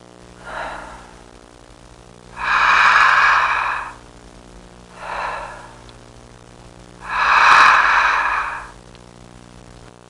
Deep Breaths Sound Effect
Download a high-quality deep breaths sound effect.
deep-breaths.mp3